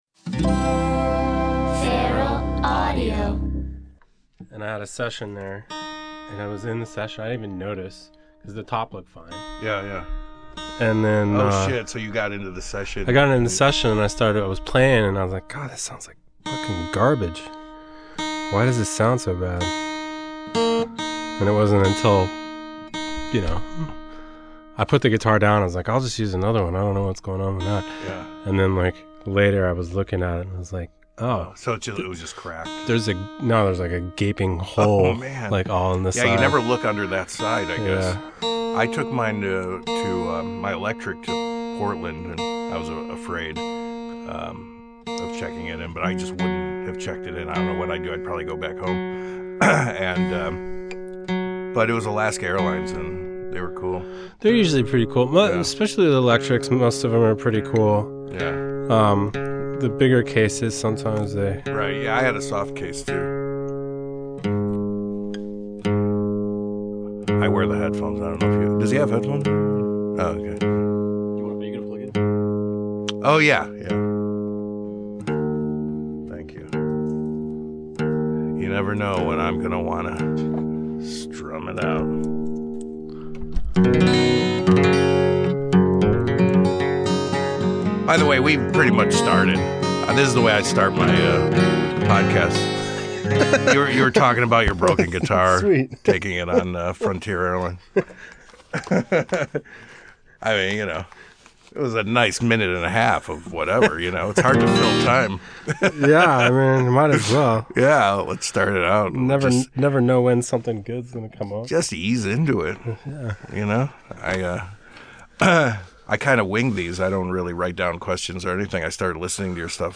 Sitting in and strumming along